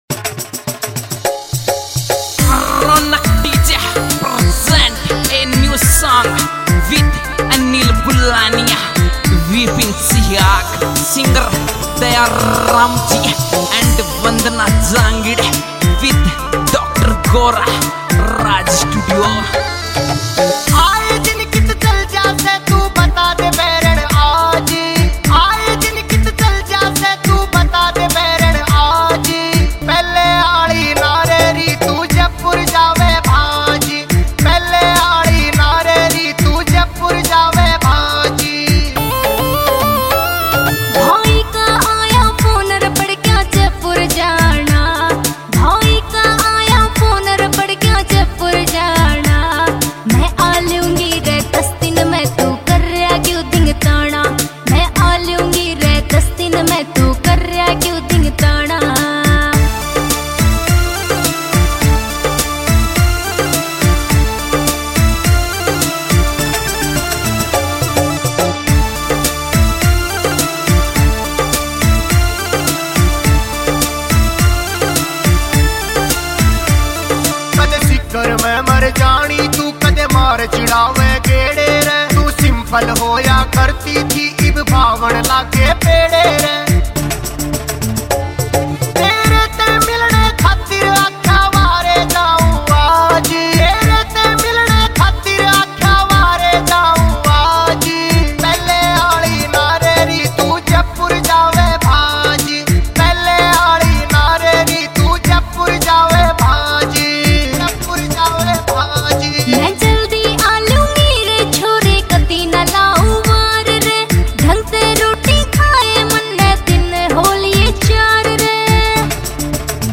» Haryanvi Songs